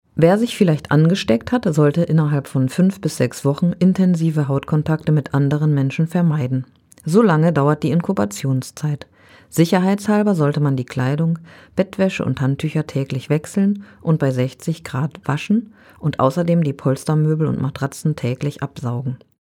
O-Töne02.05.2024